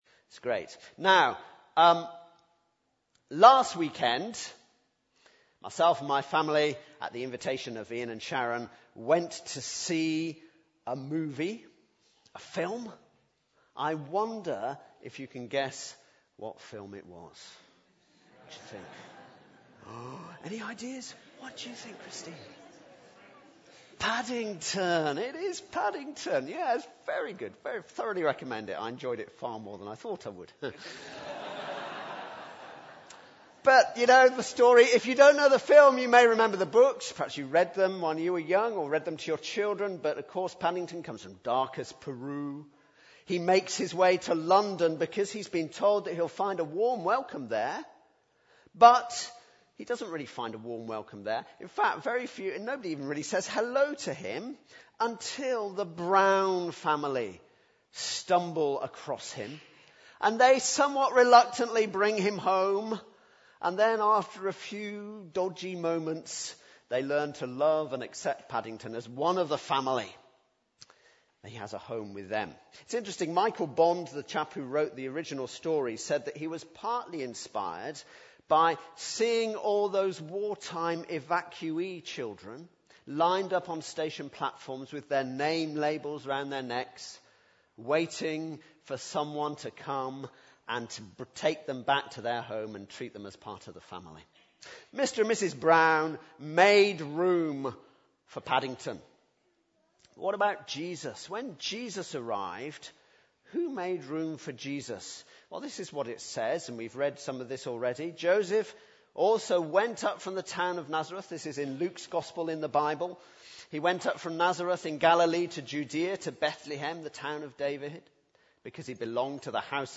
Carol Service 2014